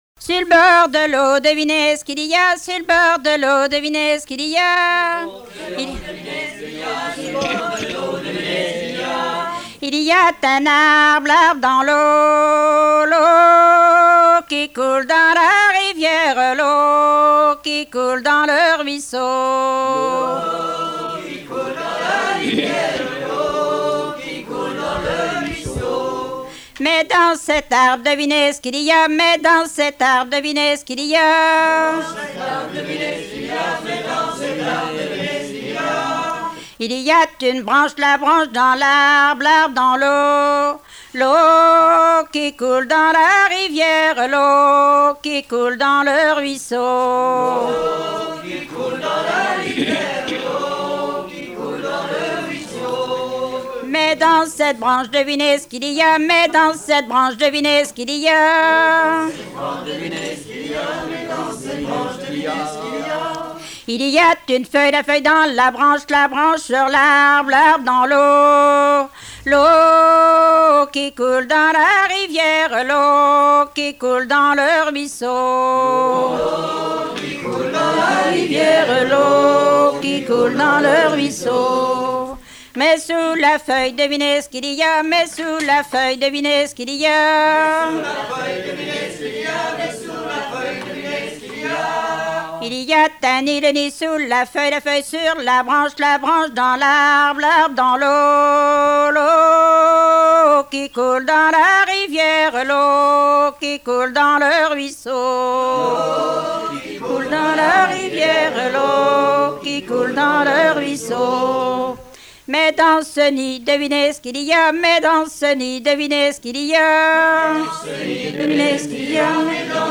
Mémoires et Patrimoines vivants - RaddO est une base de données d'archives iconographiques et sonores.
Genre énumérative
chansons populaires et traditionnelles
Pièce musicale inédite